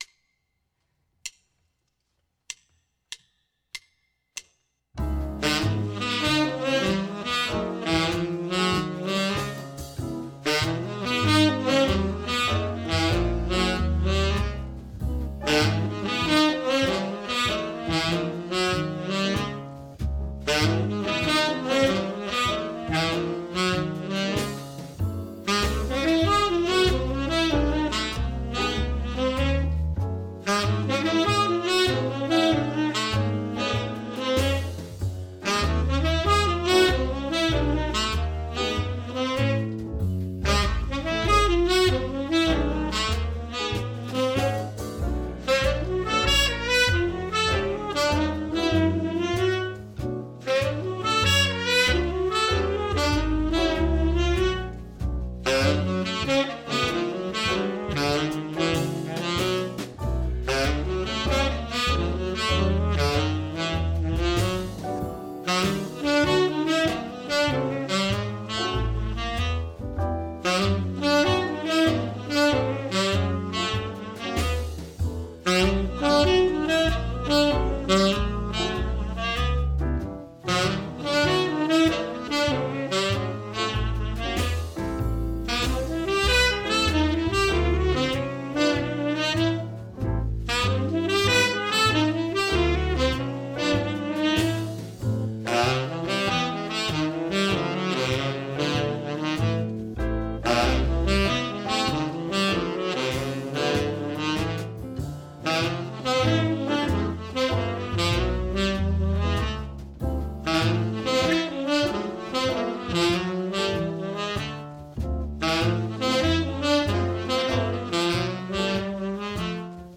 Here’s a winsome ii-V-I phrase to help strengthen your chops, develop your ear, and arouse your creative vocabulary as a improviser.